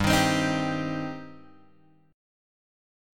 G Suspended 4th Sharp 5th